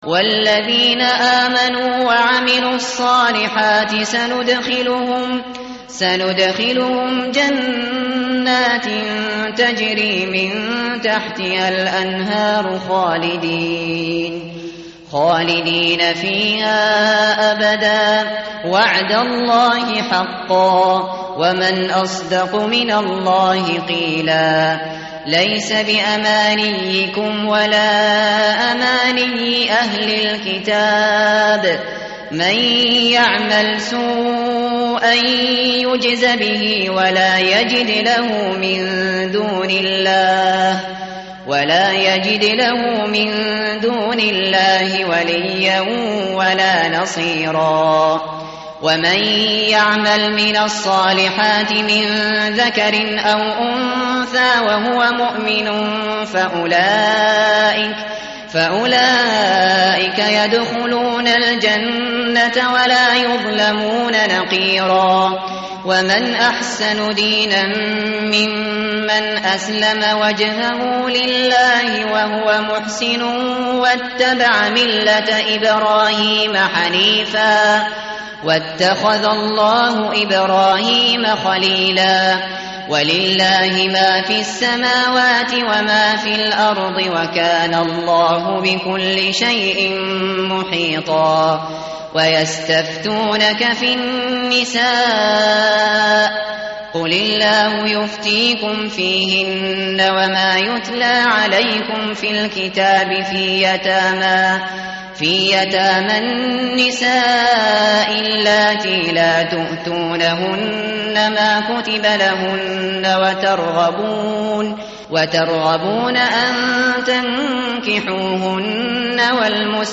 متن قرآن همراه باتلاوت قرآن و ترجمه
tartil_shateri_page_098.mp3